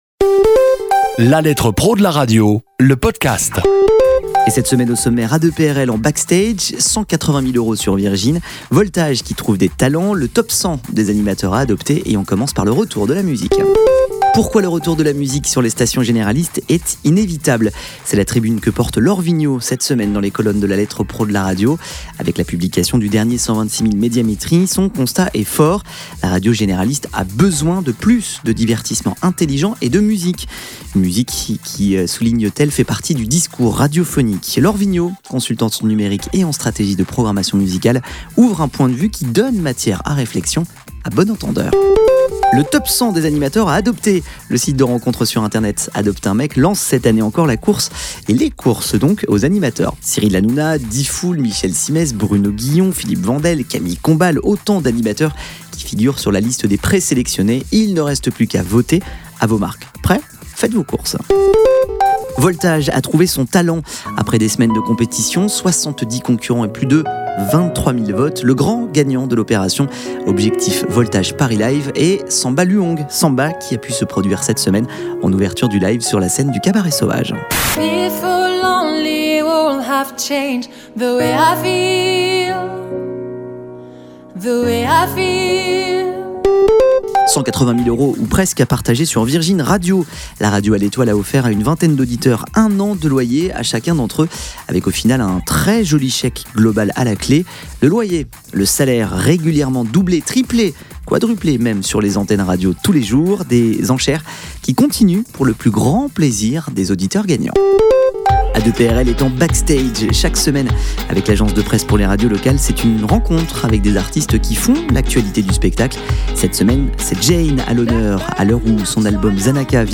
Un condensé de l'actualité de la semaine traitée ici ou dans le magazine. Cette capsule propose également des interviews exclusives de professionnels de la radio.